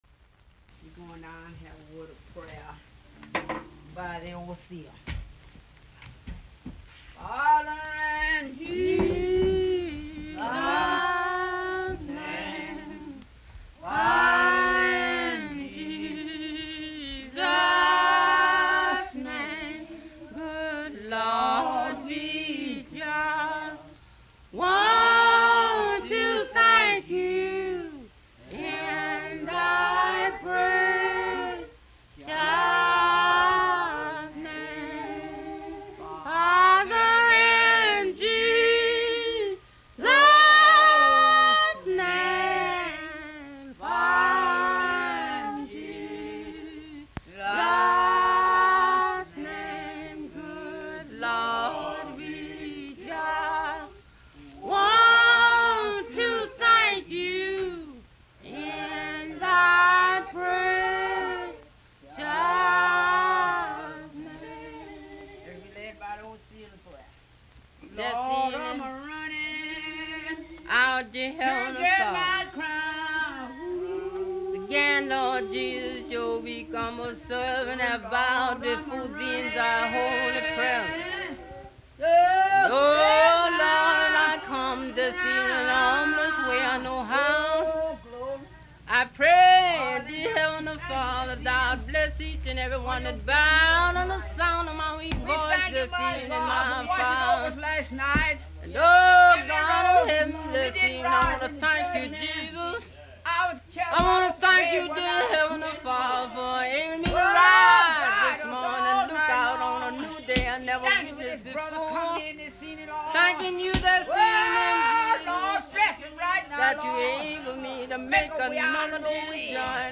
Music from the south : field recordings taken in Alabama, Lousiana and Mississippi.